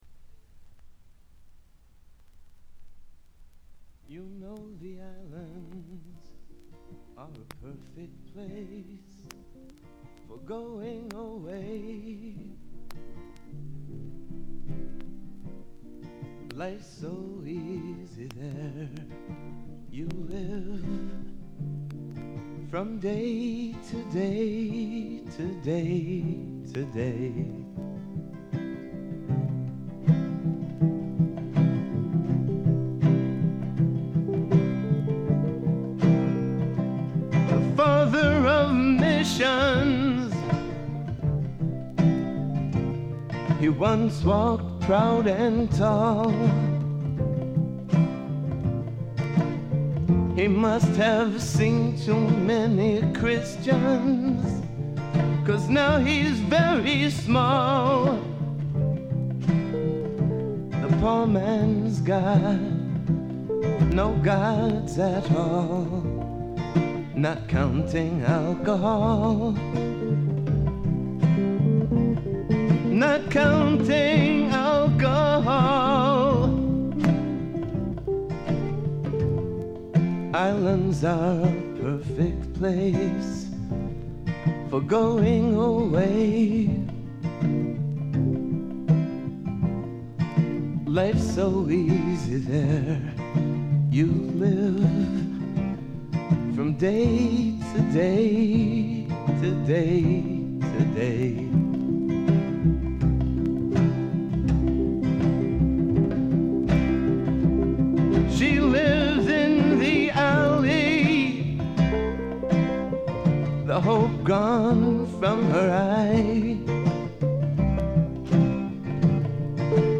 ほとんどノイズ感無し。
もともとは楽曲ライター志望だったようで曲の良さはもちろんのこと、ちょっとアシッドなヴォーカルが素晴らしいです。
メランコリックでビター＆スウィートな哀愁の名作。
試聴曲は現品からの取り込み音源です。
Guitar